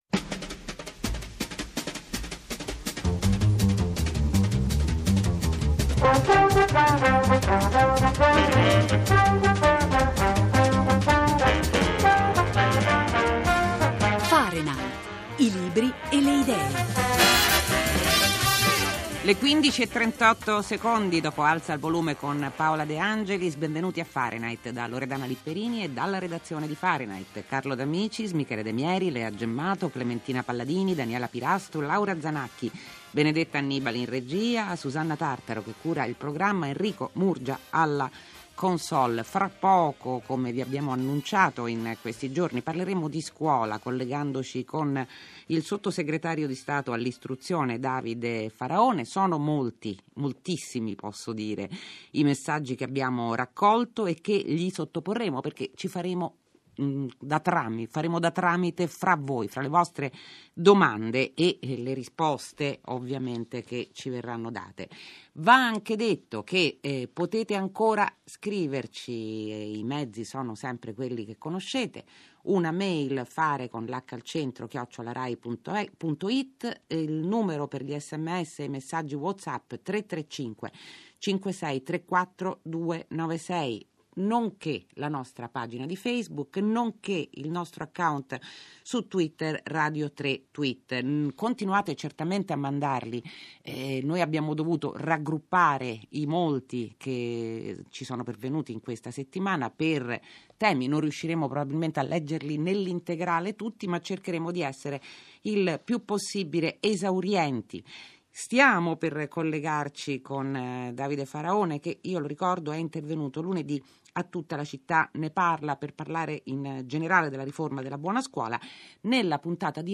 L'audio della trasmissione Fahrenheit di RaiRadio3